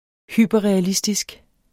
Udtale [ ˈhyˀbʌʁεaˌlisdisg ]